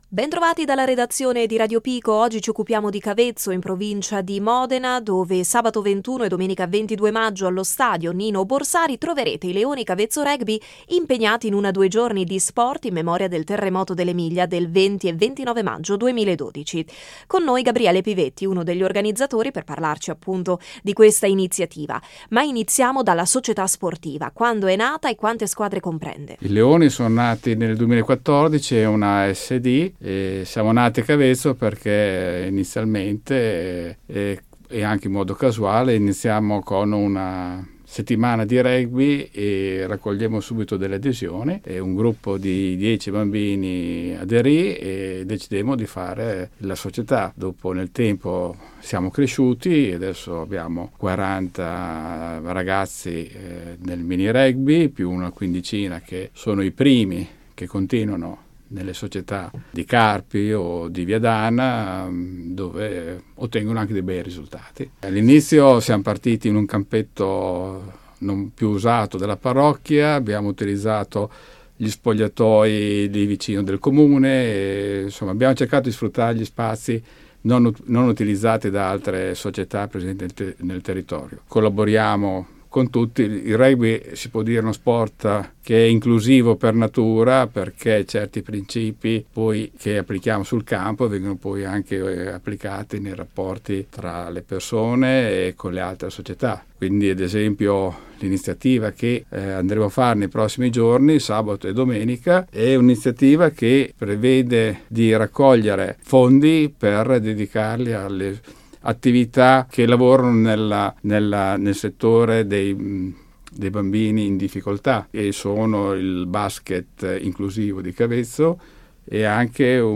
uno degli organizzatori